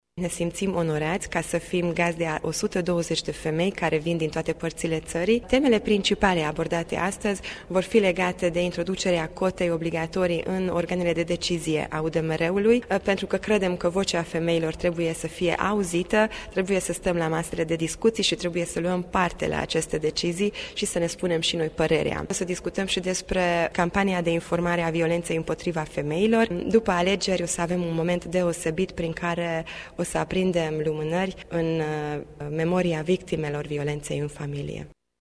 Aceasta a început la ora 10,00 în Sala mică a Palatului Culturii din municipiu în prezența mai multor personalități.
Csép Éva Andrea, deputat, președintele Organizației de femei UDMR filiala Mureș este gazda evenimentului de azi și ne-a spus care sunt temele principale ale ședinței: